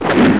Kick-roundhouse.wav